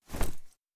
关闭页面声.wav